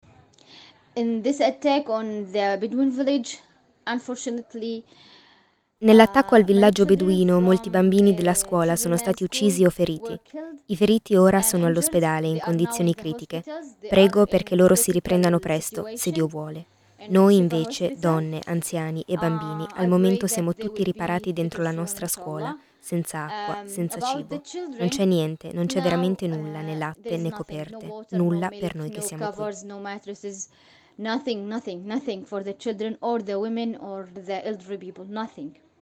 La testimonianza raccolta questo pomeriggio da un villaggio della Striscia di Gaza.